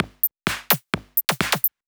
Electrohouse Loop 128 BPM (7).wav